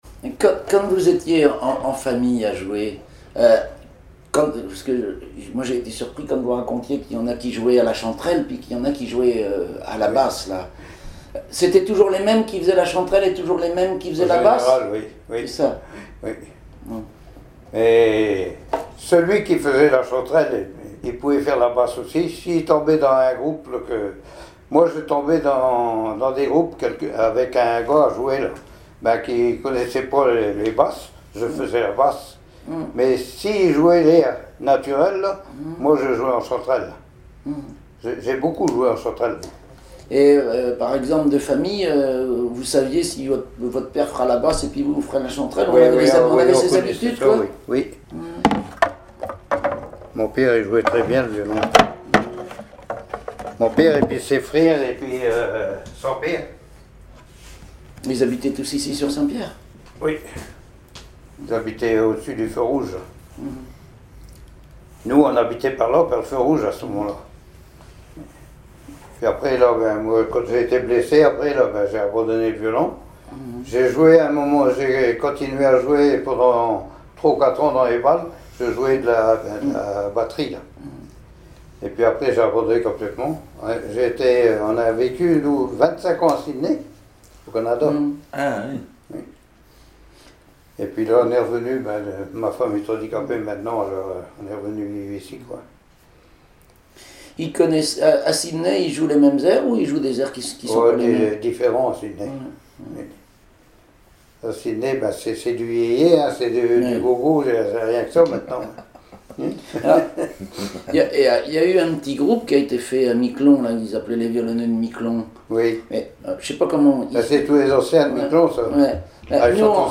Manière de jouer à deux voix à deux violons
violoneux, violon,
Témoignage